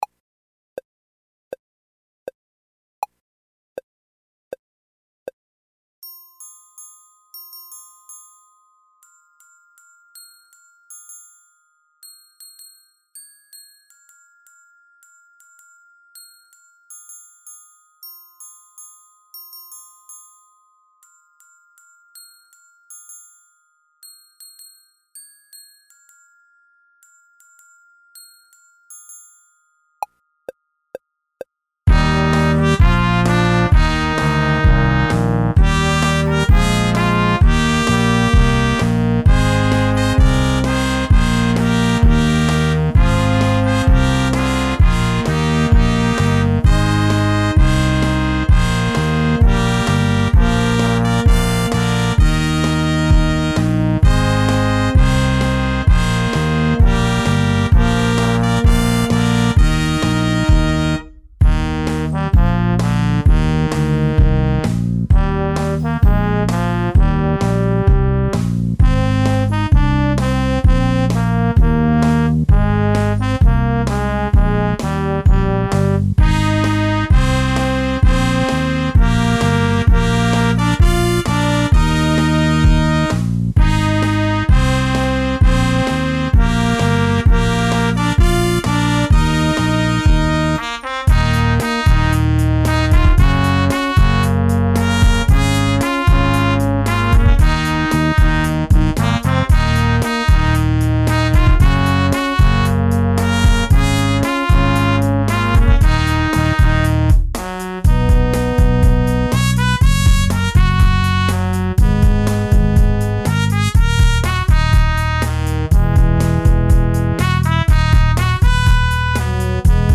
Trp 1 Trp 2 Pos 1 Pos 2 Horn BDrum Lyr Sax